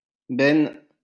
Beine (French pronunciation: [bɛn]